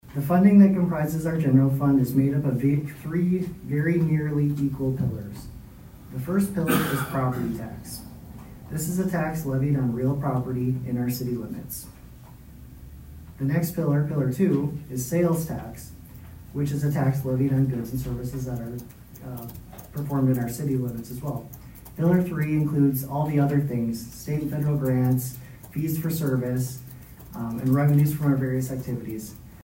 ABERDEEN, S.D.(HubCityRadio)- On Monday night, the Aberdeen City Council did a work session to address the budget for the year 2026.